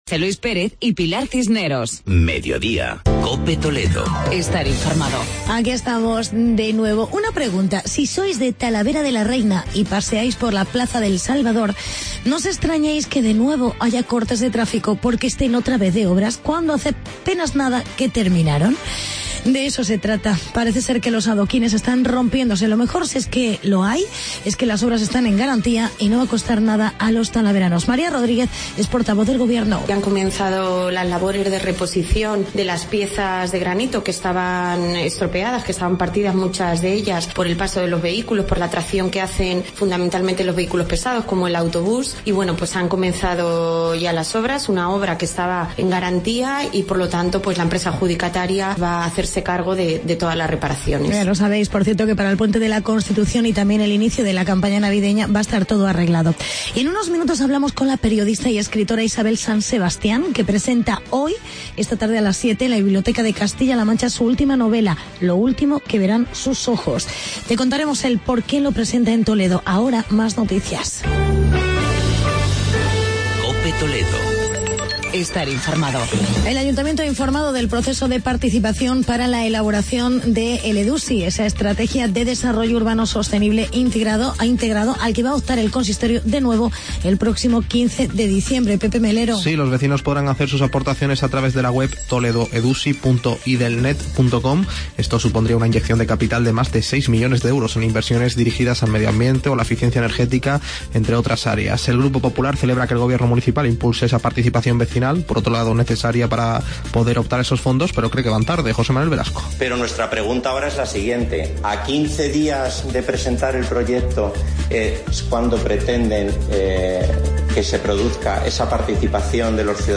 Actualidad y entrevista con la escritora y periodista Isabel San Sebastián con la presentación de su libro "Lo último que verán sus ojos".